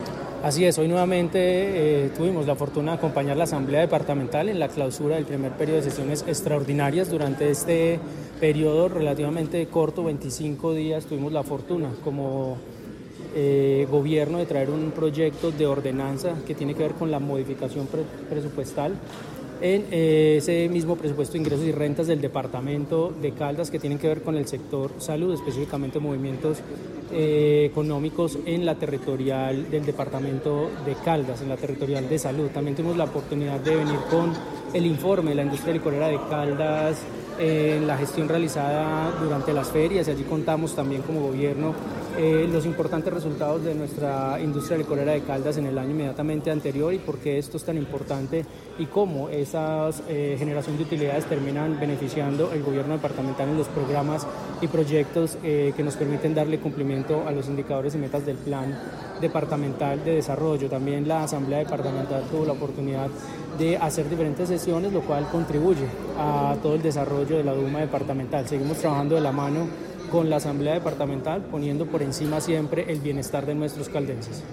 Gobernador (e) de Caldas, Ronald Fabián Bonilla.
Ronald-Fabian-Bonilla-Gobernador-e-.mp3